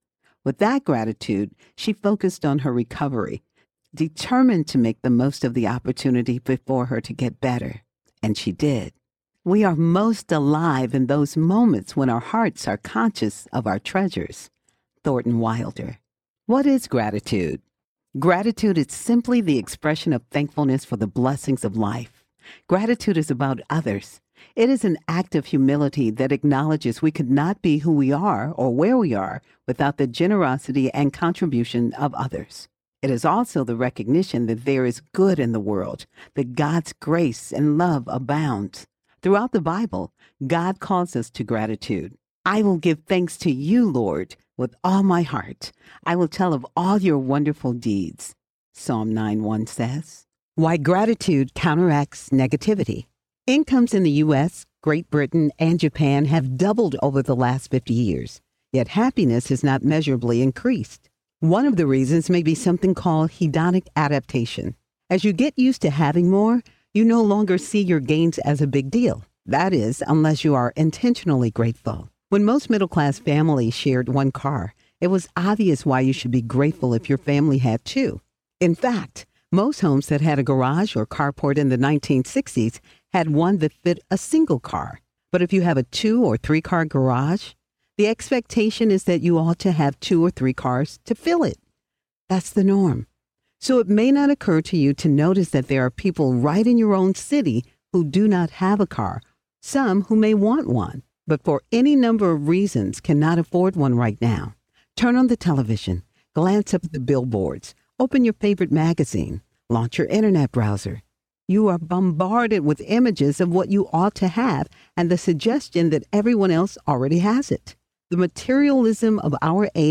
Happy Women Live Better Audiobook
Narrator
5.95 Hrs. – Unabridged